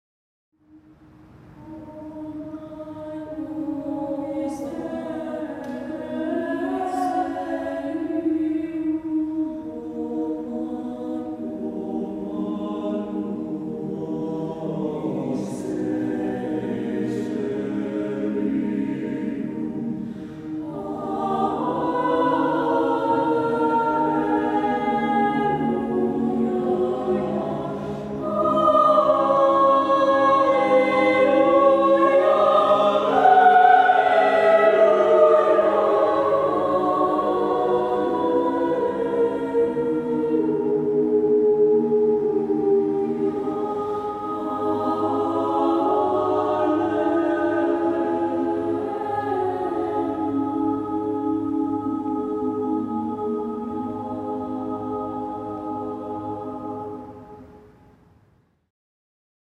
SATB choir (a cappella)